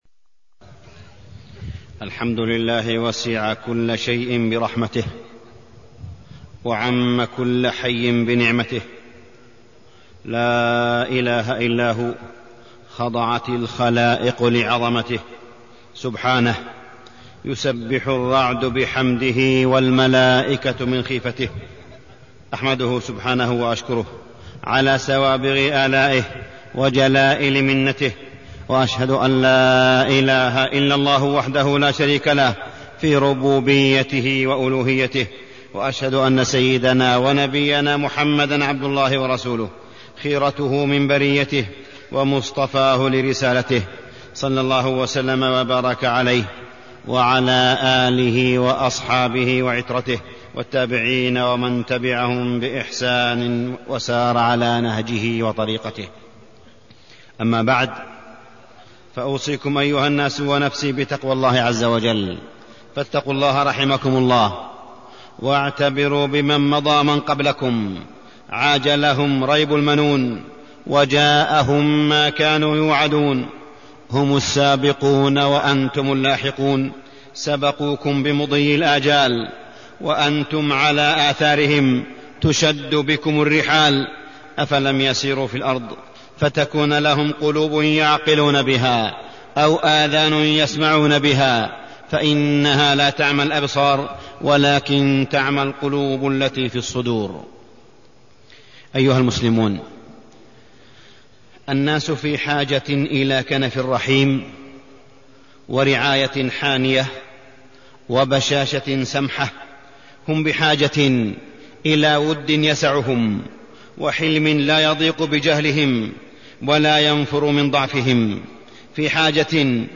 تاريخ النشر ١٥ محرم ١٤٢٣ هـ المكان: المسجد الحرام الشيخ: معالي الشيخ أ.د. صالح بن عبدالله بن حميد معالي الشيخ أ.د. صالح بن عبدالله بن حميد الرحمة